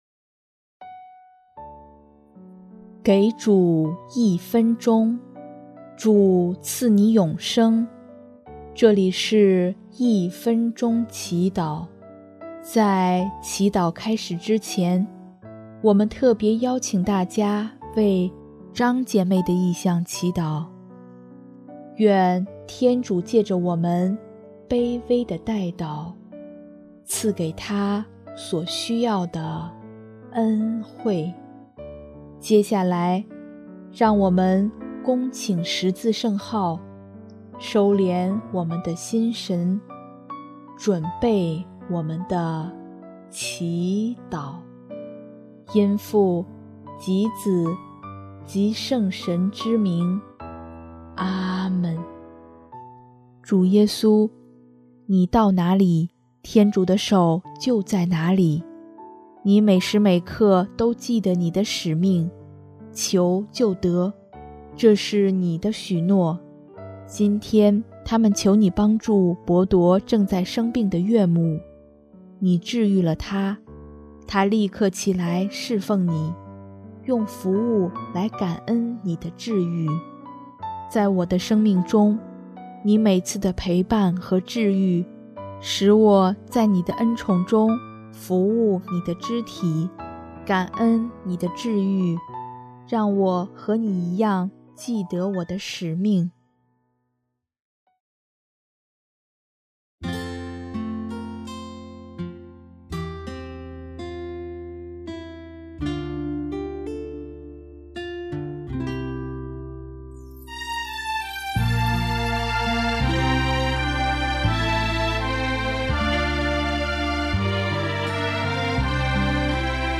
音乐： 第二届华语圣歌大赛参赛歌曲《一生寻求你》